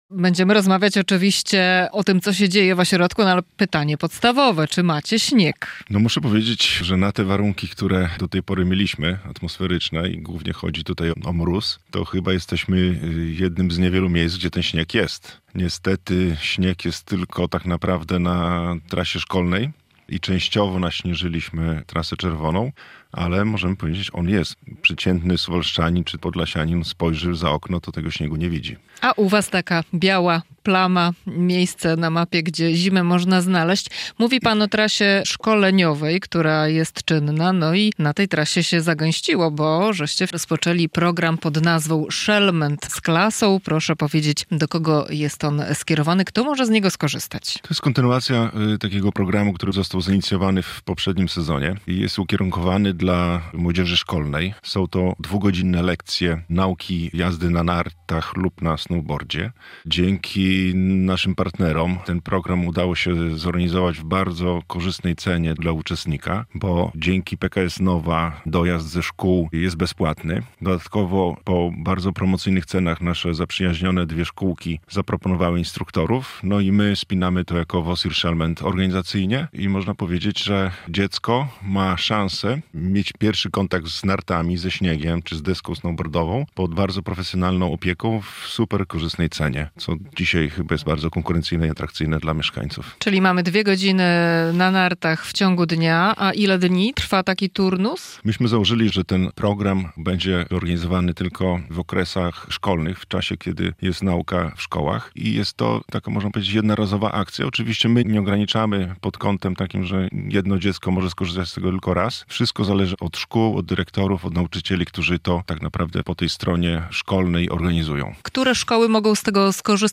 rozmawia